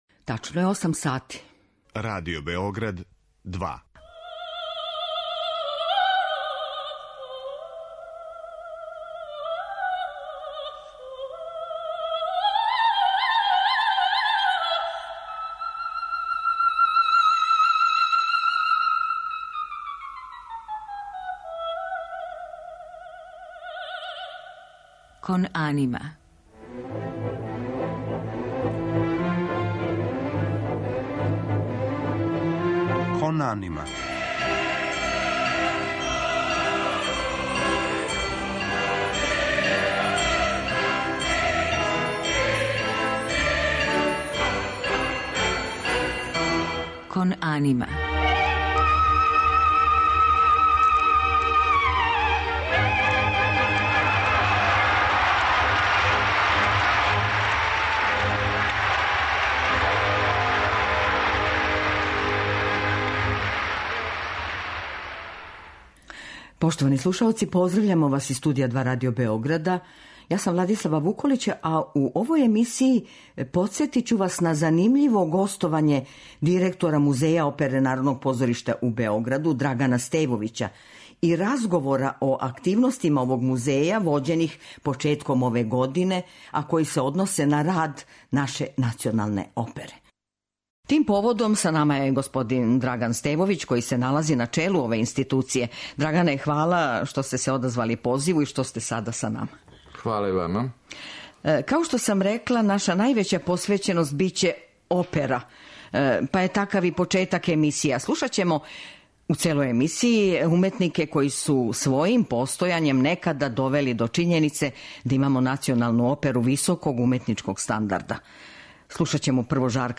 Емисија посвећена опери
Данашња емисија Кон анима има два сегмента.